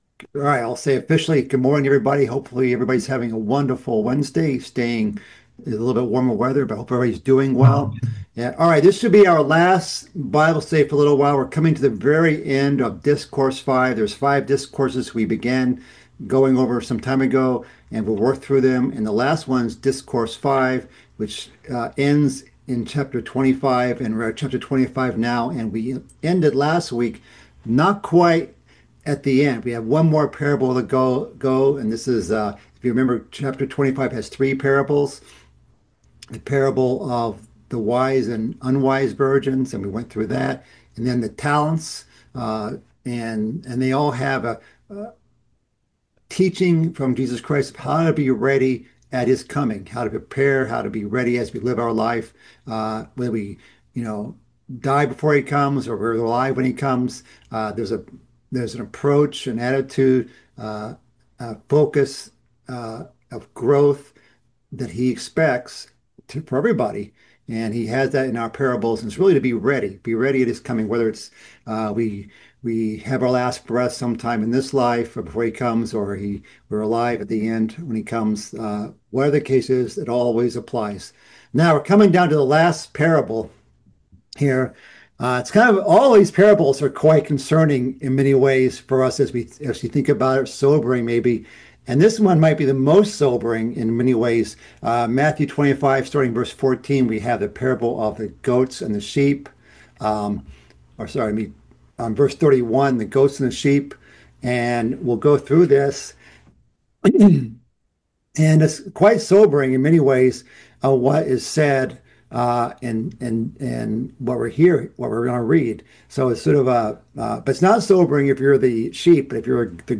This is the twelfth and final part of a mid-week Bible study series covering Christ's fifth discourse in the book of Matthew.